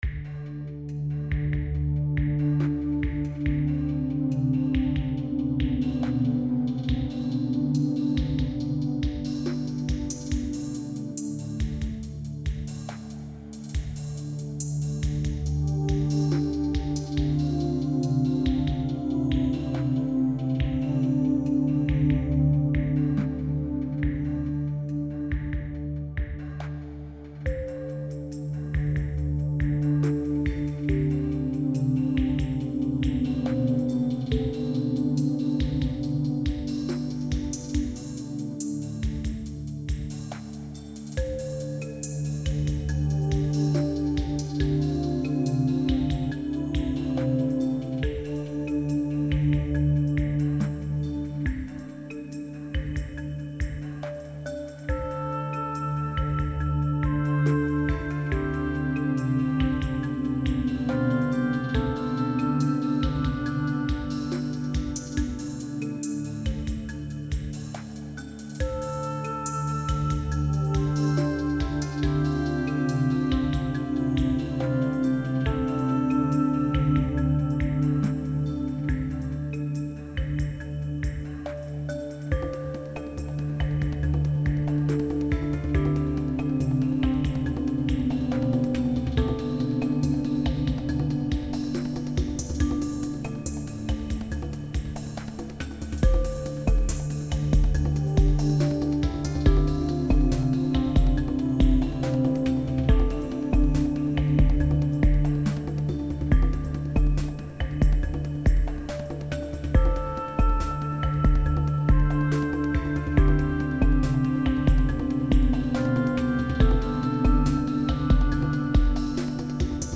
These are links to some sample pieces that I have composed using soundfonts and samples.
Sealed - Halo-esque theme designed for looping.